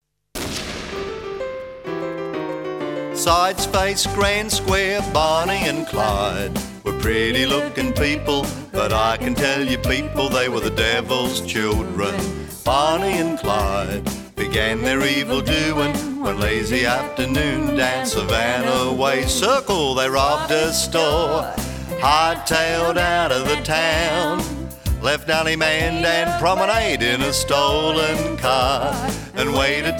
Instrumental
Vocal